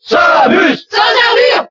Category:Crowd cheers (SSBB) You cannot overwrite this file.
Zero_Suit_Samus_Cheer_French_SSBB.ogg